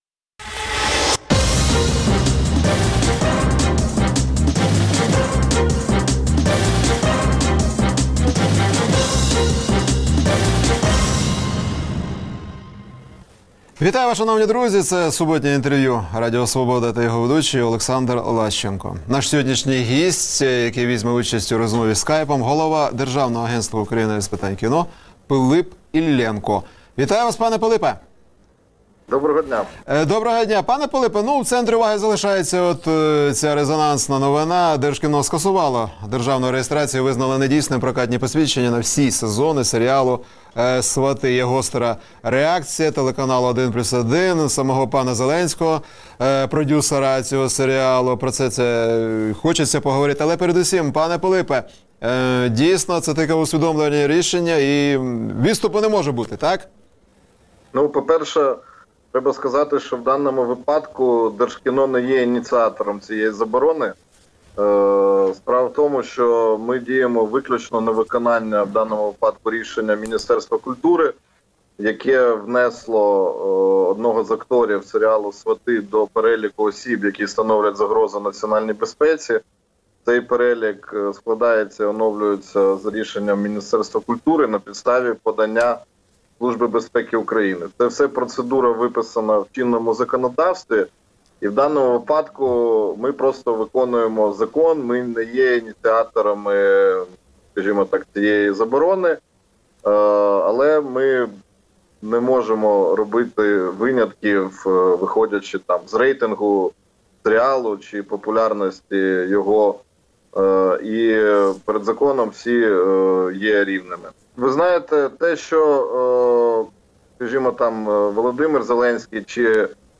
Суботнє інтвер’ю - розмова про актуальні проблеми тижня.